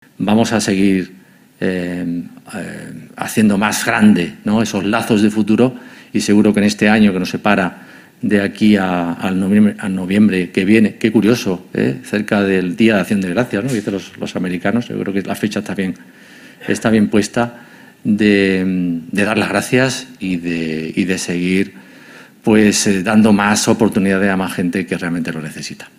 La sede madrileña de Fundación ONCE reunió a estas 25 empresas y entidades en el  acto ‘Lazos de futuro’, celebrado el 18 de noviembre de manera presencial y telemática, con el objetivo de expresar públicamente el agradecimiento de las fundaciones del Grupo Social ONCE a los particulares, empresas e instituciones que colaboran con ellas a través de programas, proyectos e iniciativas que ayudan a avanzar en la igualdad de los derechos de las personas con discapacidad y sus familias, y por su compromiso con la mejora de la calidad de vida de las personas con discapacidad.